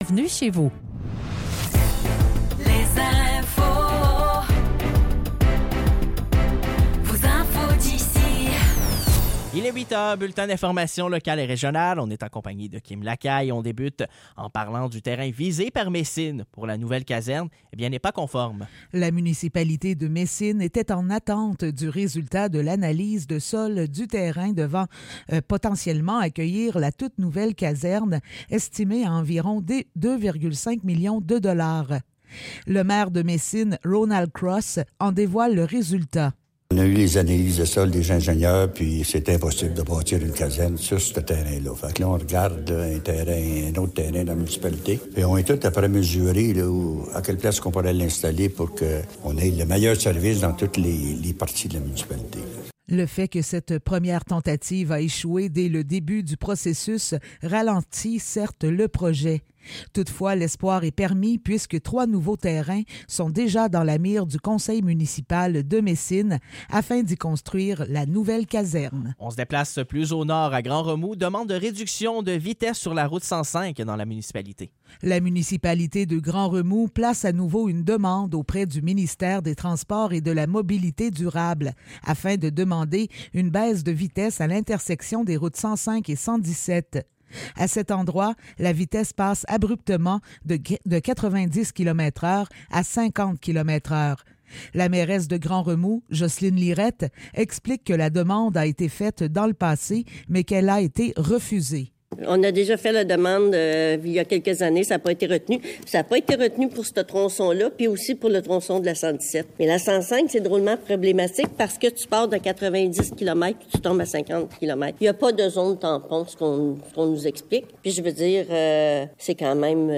Nouvelles locales - 11 mars 2024 - 8 h